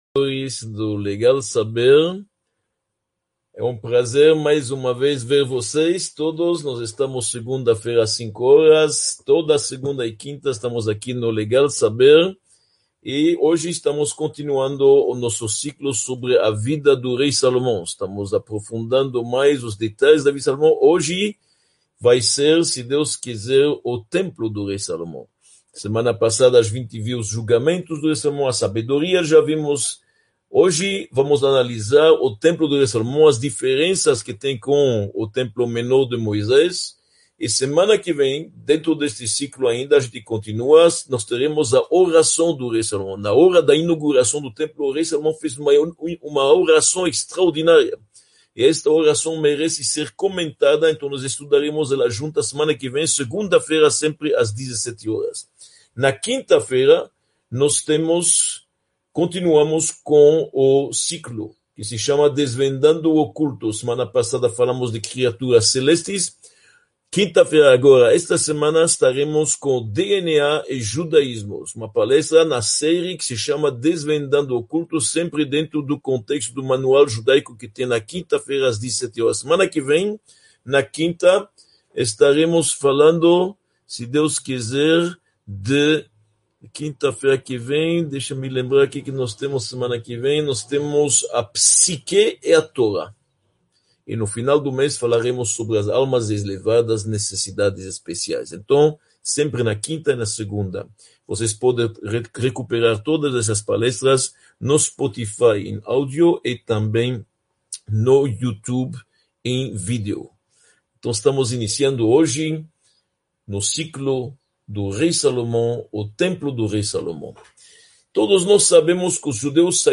Aula 4